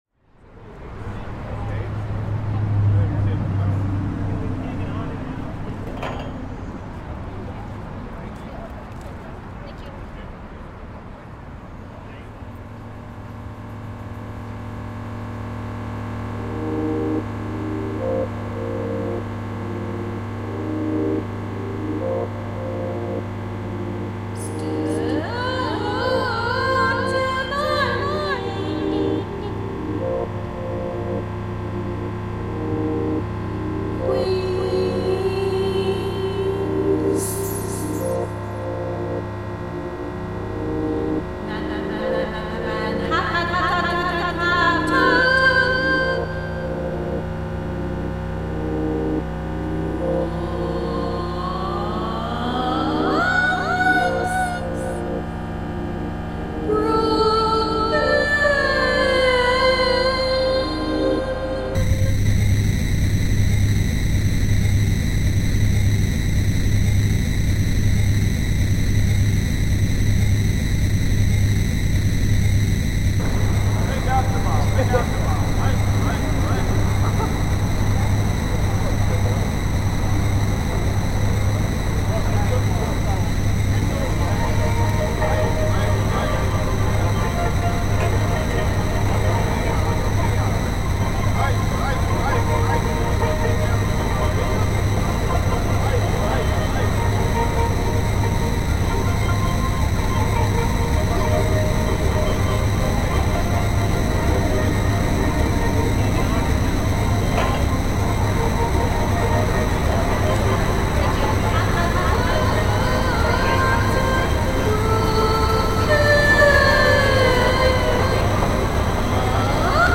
Brooklyn Bridge reimagined